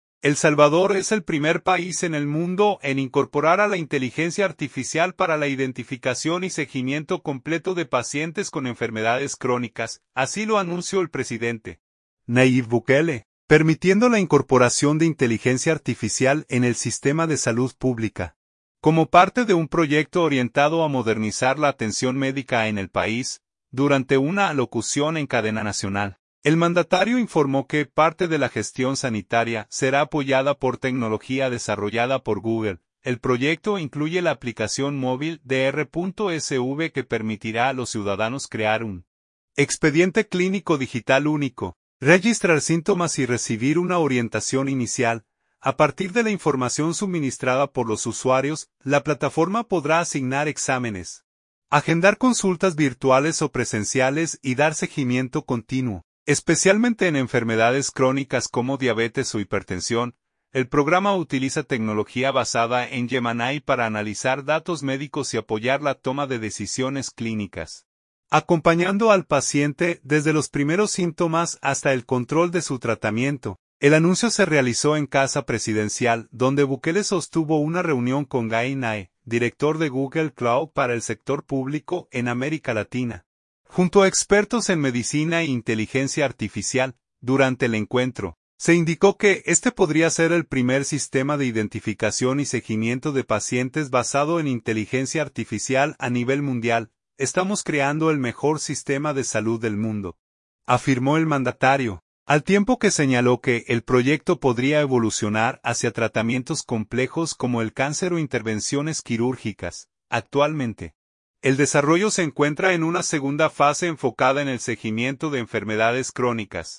Durante una alocución en cadena nacional, el mandatario informó que parte de la gestión sanitaria será apoyada por tecnología desarrollada por Google.